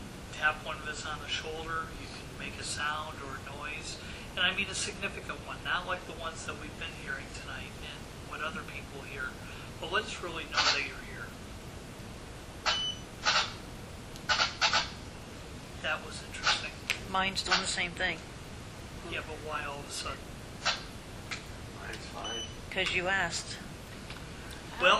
Electronic Voice Phenomenon (EVP)
The radios of all tam members inexplicably squelched. This had not happened the entire night prior to or after this once incident.